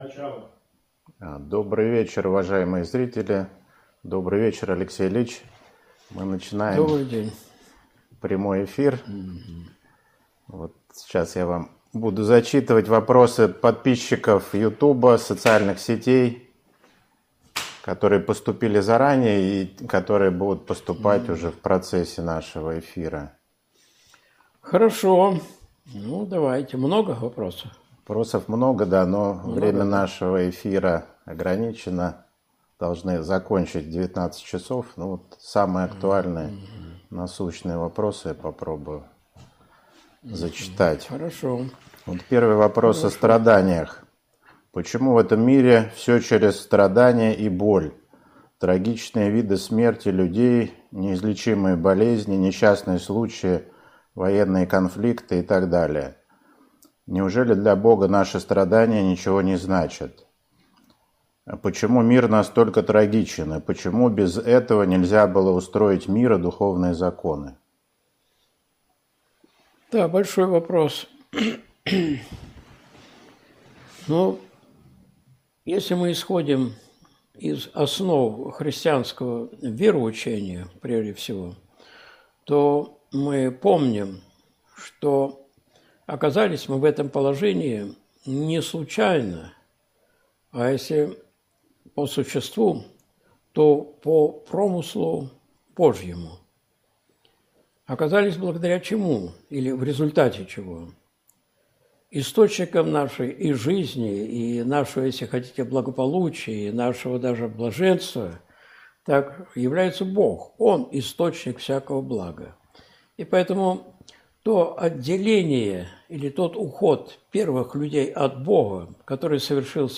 С Богом ничего не страшно! Часть 1 (Прямой эфир, 03.10.2023)